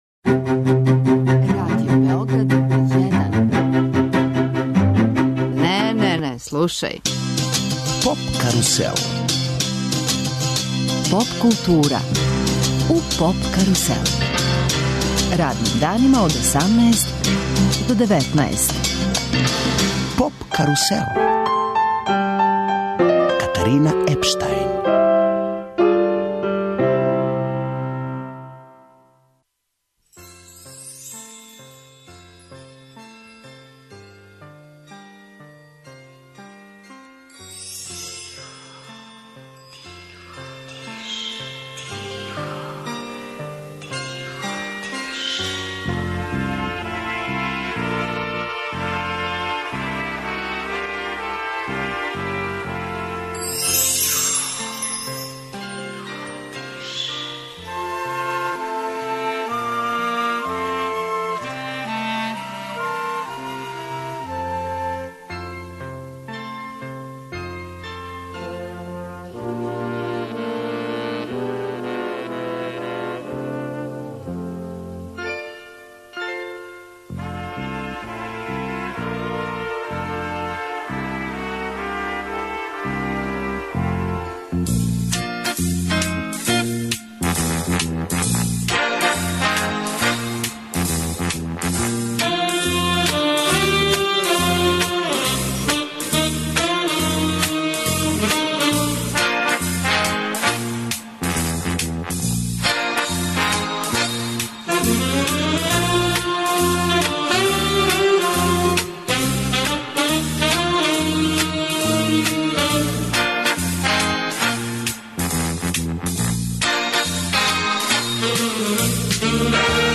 Јављамо се из Ваљева са 30. Џез фестивала...